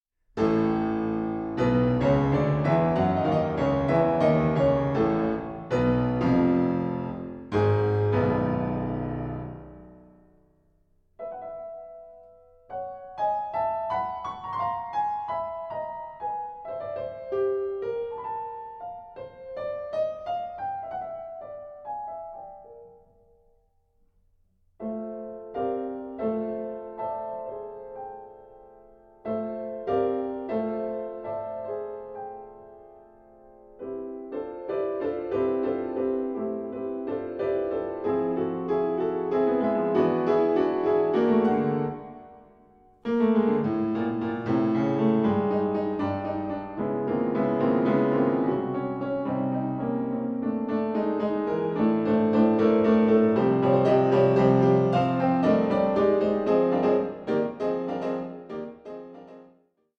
Rhapsody for violin and piano